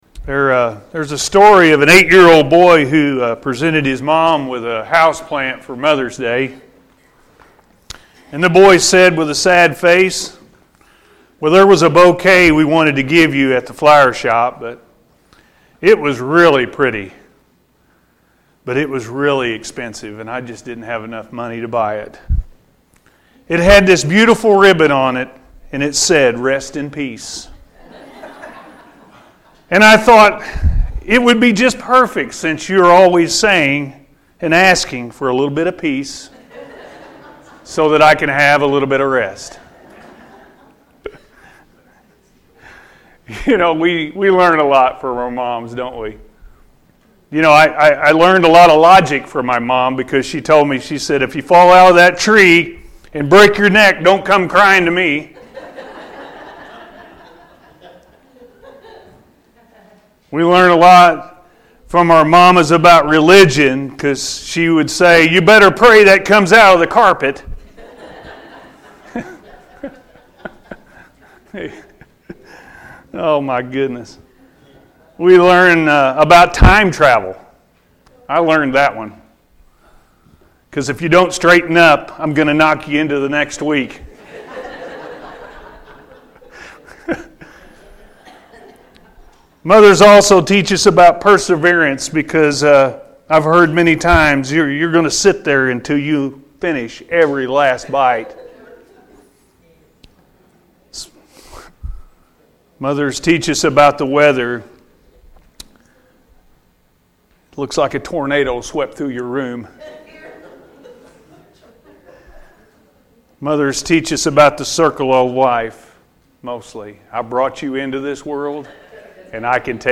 Lessons Learned From A Mother-A.M. Service